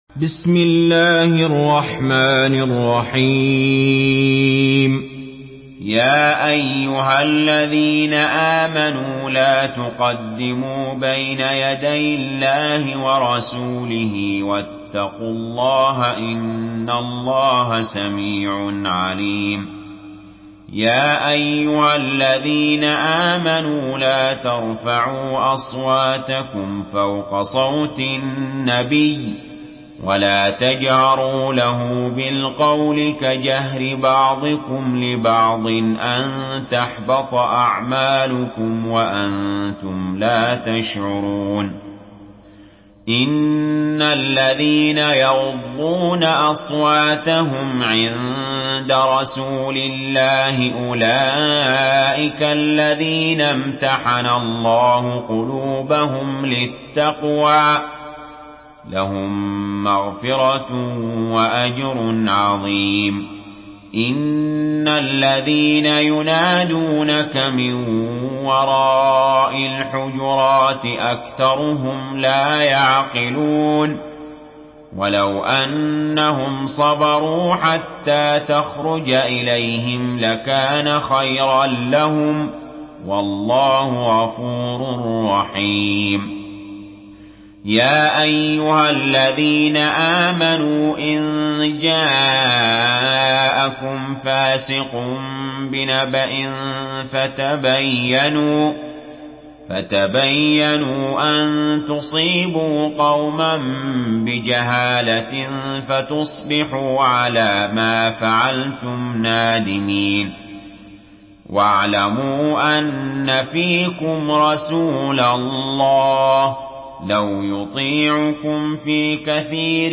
سورة الحجرات MP3 بصوت علي جابر برواية حفص
مرتل